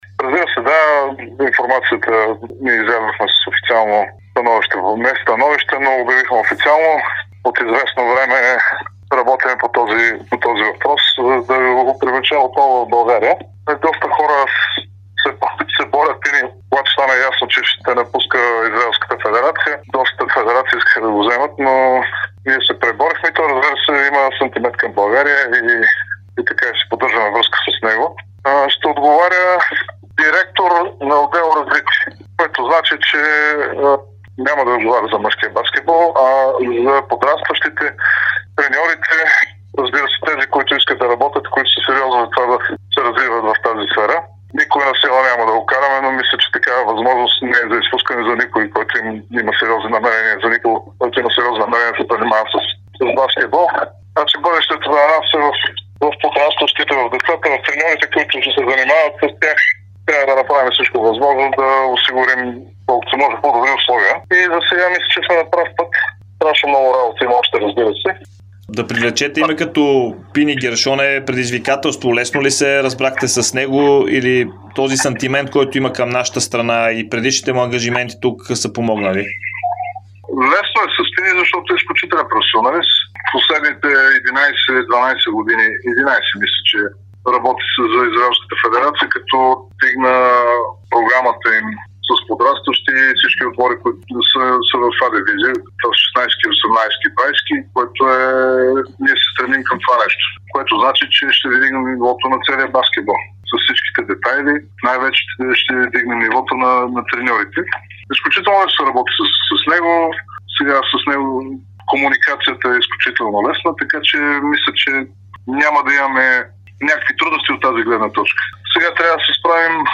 даде специално интервю за Дарик радио и Dsport. В него той говори за назначаването на легендарния Пини Гершон като директор „Развитие“ към централата.